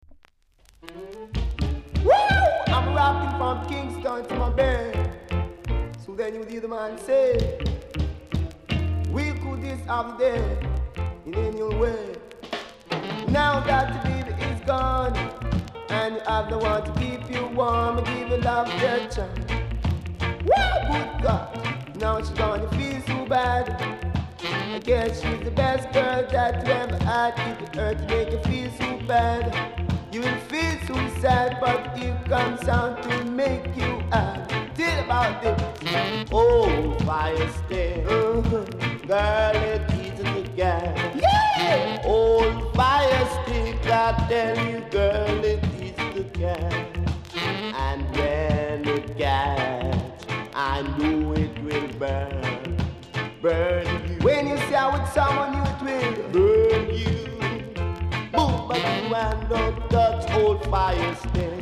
※裏面ややチリパチします。